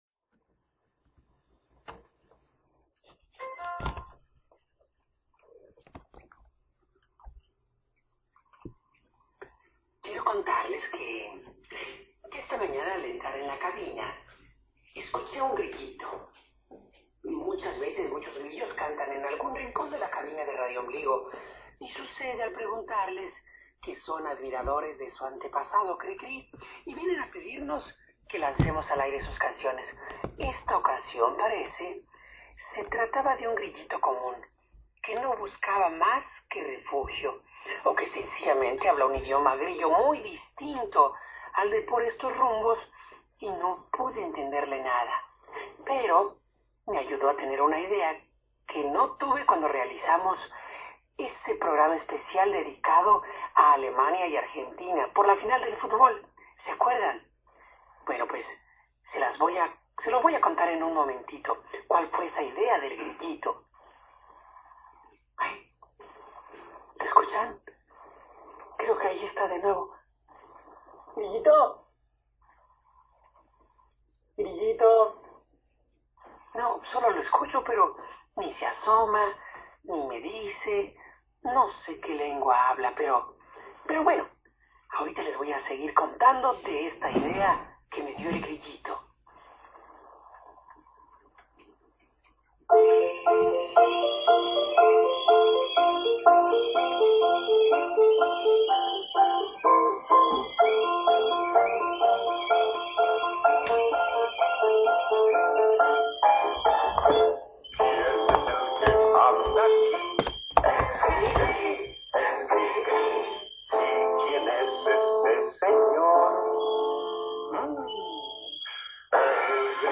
Si oyen ruiditos extraños échenle a él la culpa.